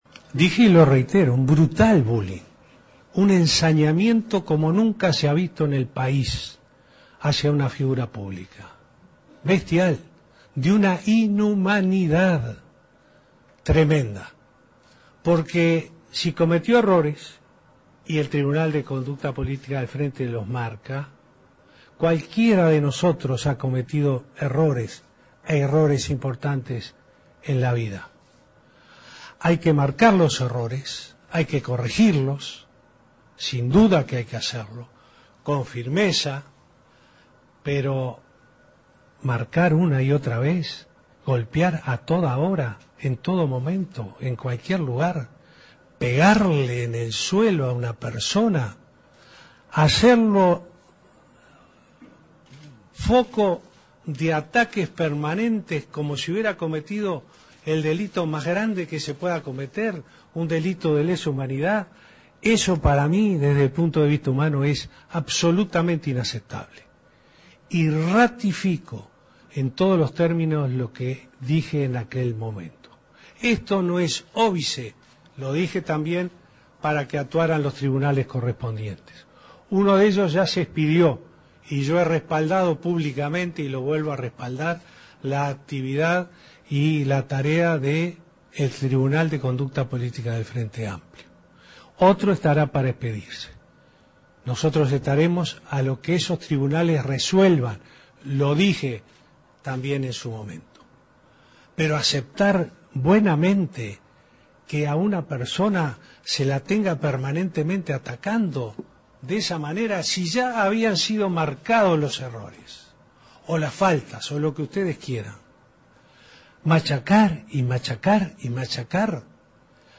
El presidente de la República, Tabaré Vázquez, dedicó la conferencia de prensa posterior al Consejo de Ministros para referirse a la renuncia presentada por el vicepresidente, Raúl Sendic, ante el Plenario del Frente Amplio.